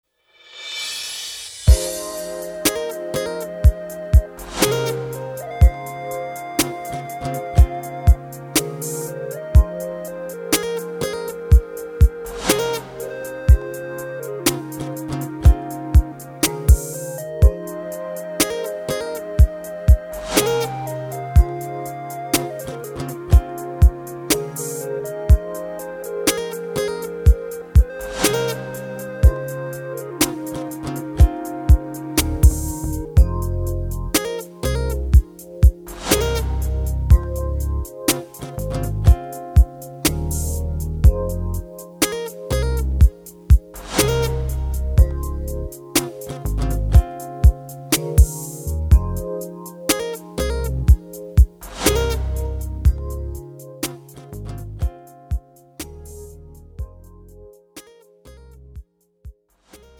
미리듣기
장르 pop 구분 Premium MR